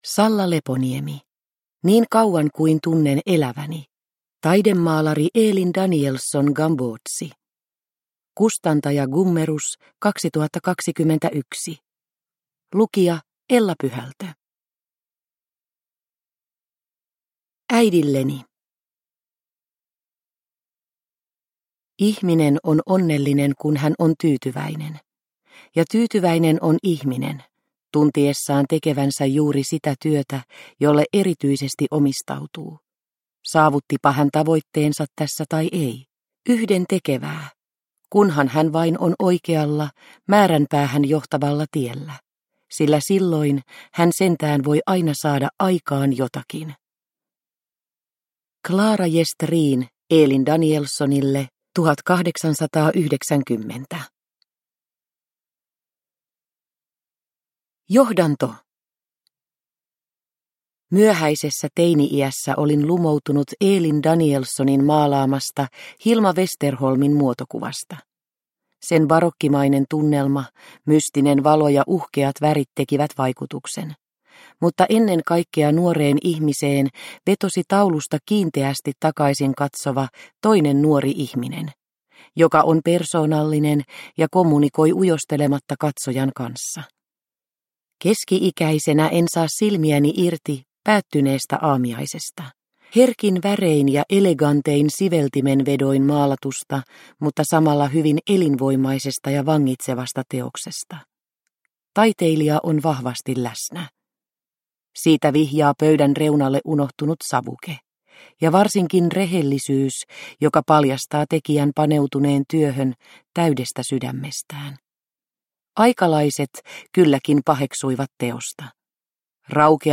Niin kauan kuin tunnen eläväni – Ljudbok – Laddas ner